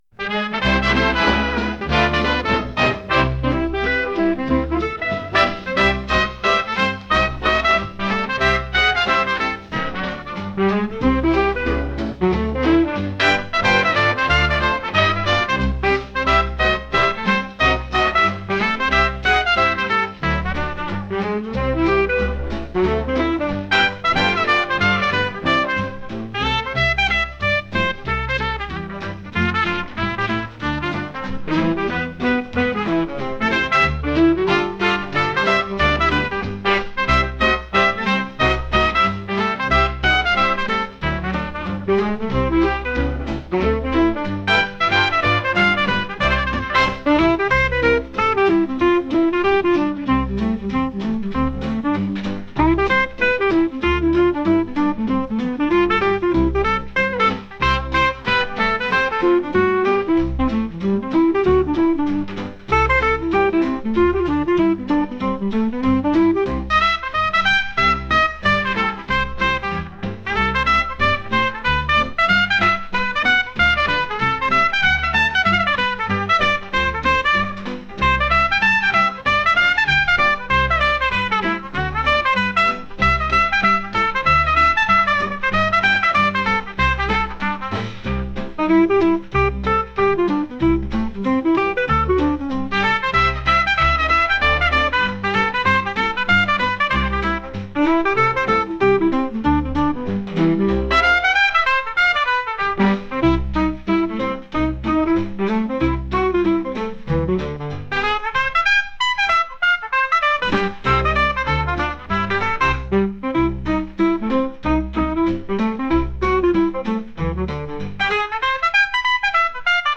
ちょっと時代がかったジャズ曲です。